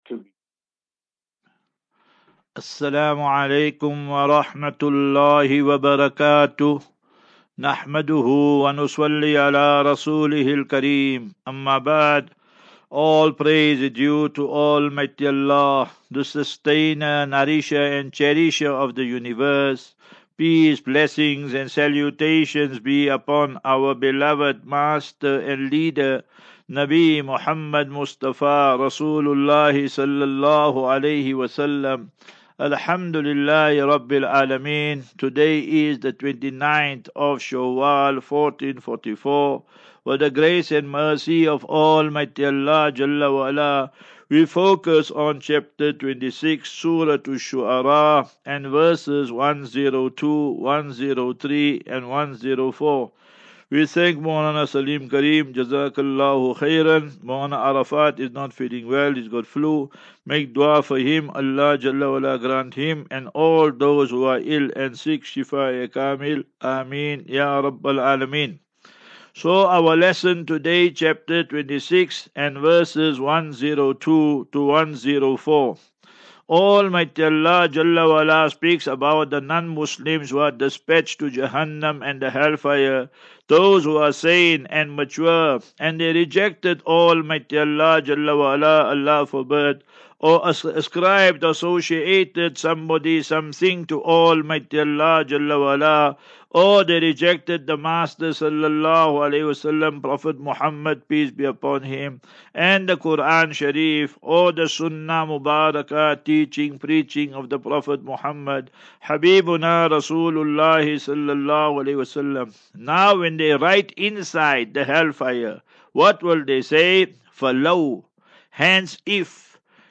View Promo Continue Install As Safinatu Ilal Jannah Naseeha and Q and A 20 May 20 May 23 Assafinatu